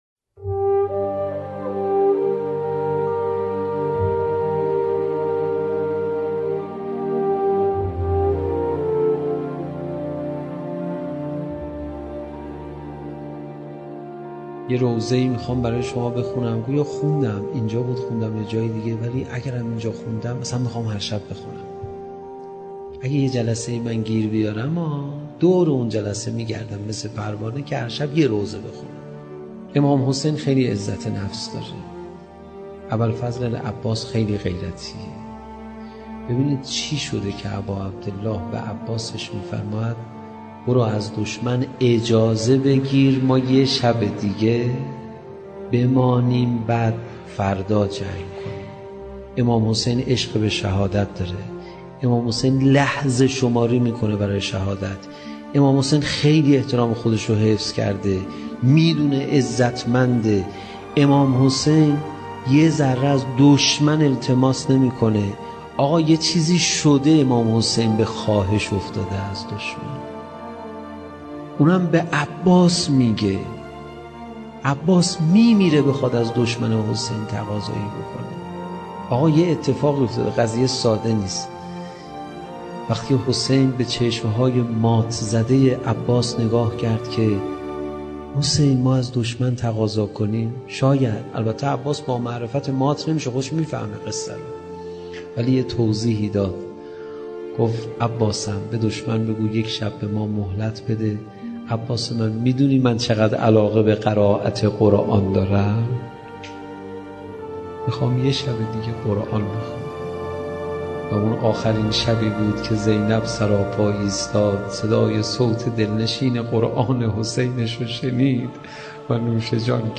روضه قرآنی